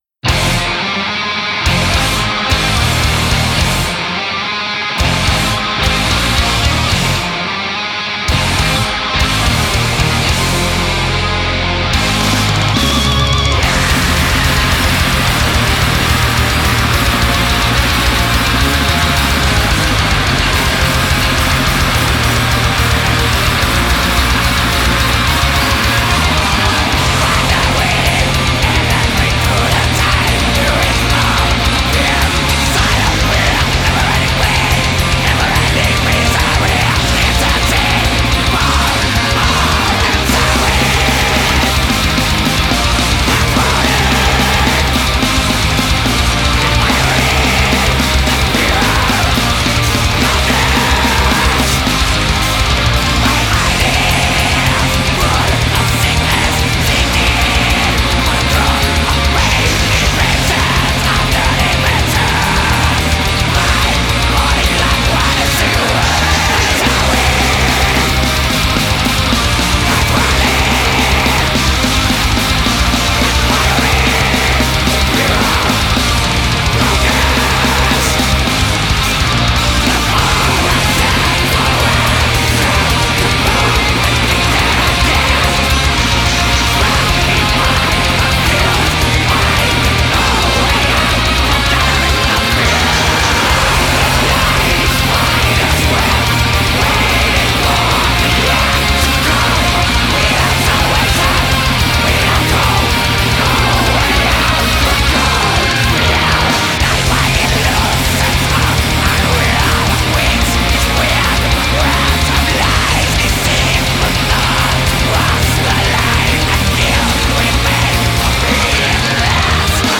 Death Metal